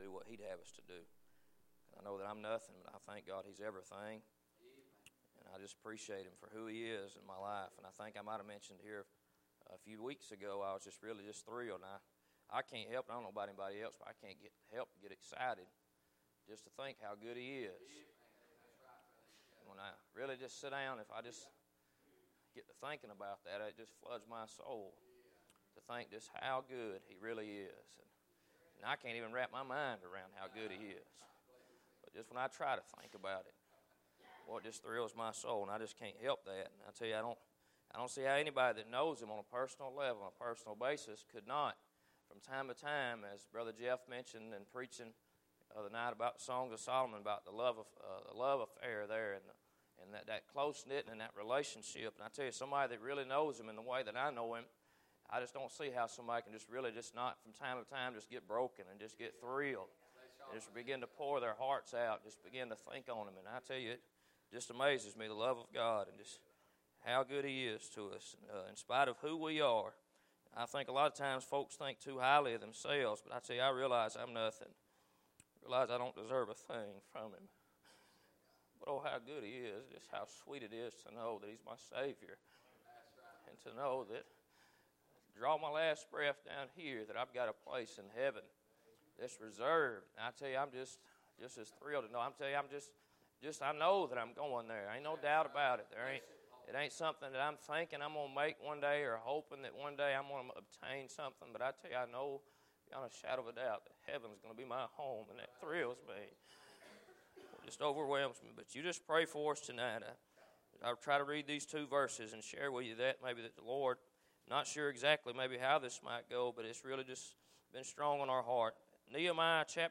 01-Message_-A-Mind-To-Work.mp3